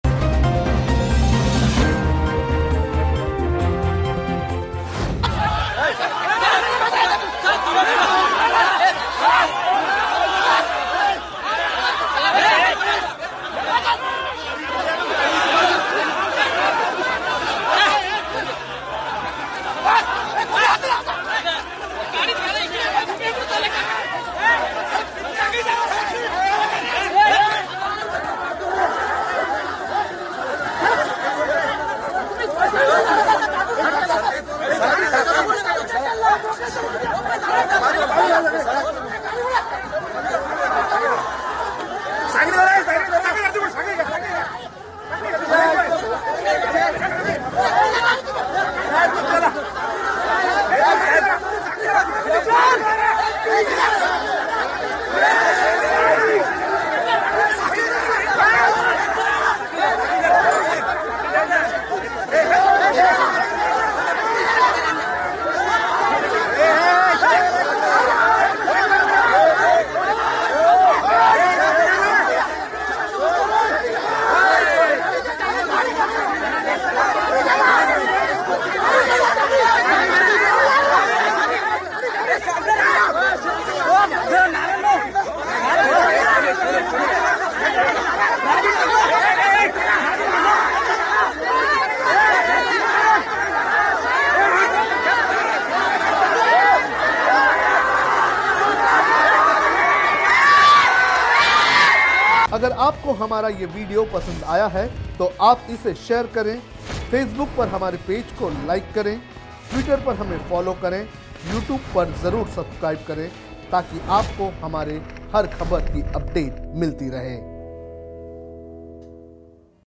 न्यूज़ रिपोर्ट - News Report Hindi / मराठा आरक्षण : पिट गए शिवसेना के सांसद चंद्रकांत खैरे